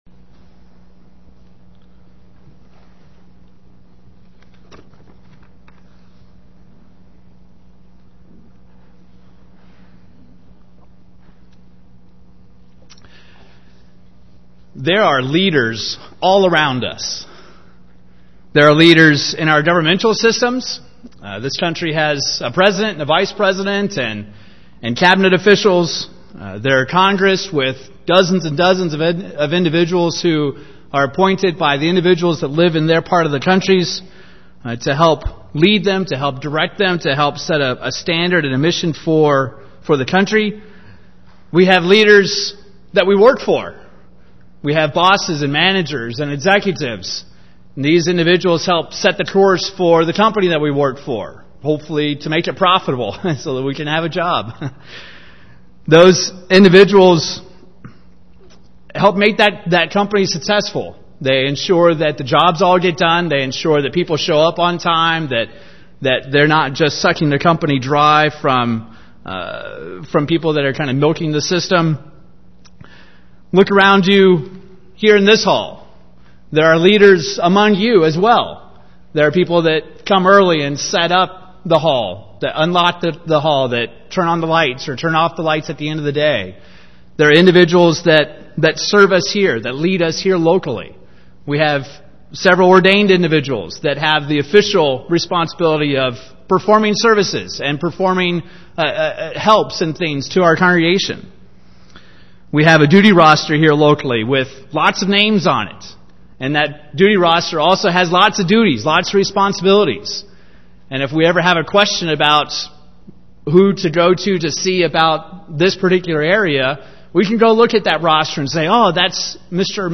These are the notes taken live during services as captioning for the deaf and hard of hearing.
UCG Sermon Notes These are the notes taken live during services as captioning for the deaf and hard of hearing.